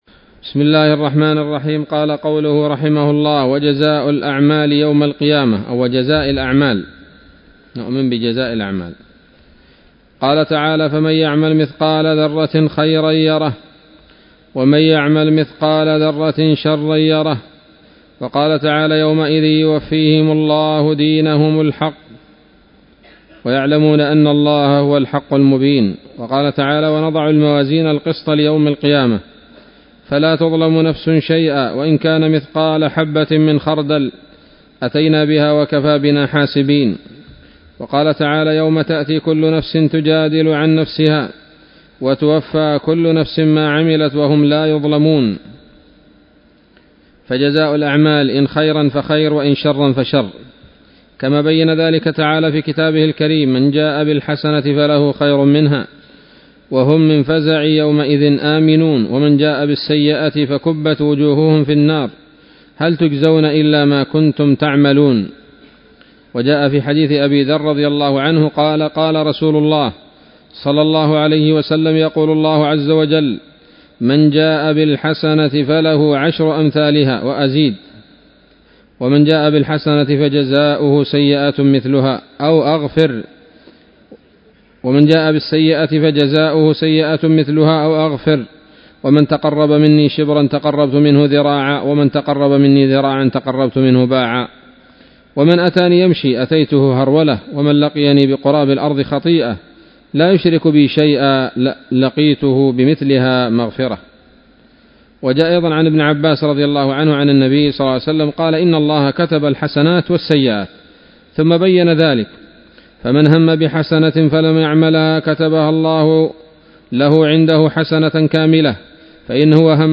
الدرس الثاني عشر بعد المائة